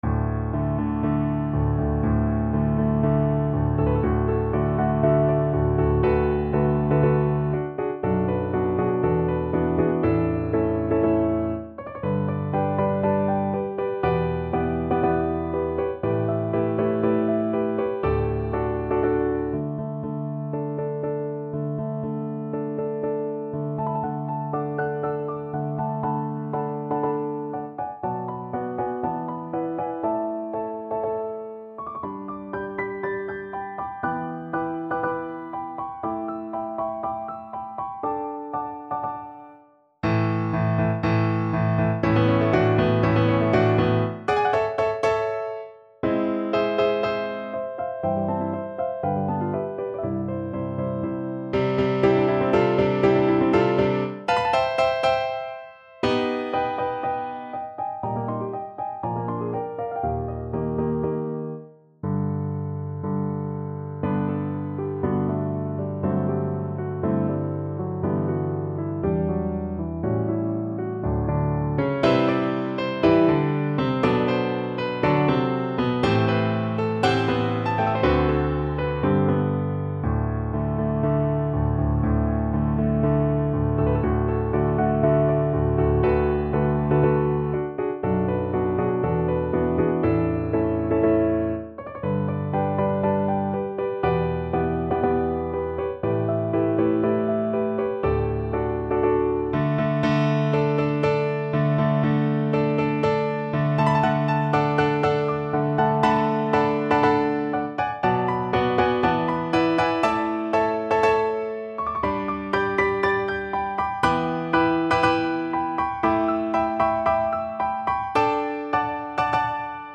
Free Sheet music for Piano
No parts available for this pieces as it is for solo piano.
D major (Sounding Pitch) (View more D major Music for Piano )
4/4 (View more 4/4 Music)
~ = 120 Tempo di Marcia un poco vivace
Piano  (View more Intermediate Piano Music)
Classical (View more Classical Piano Music)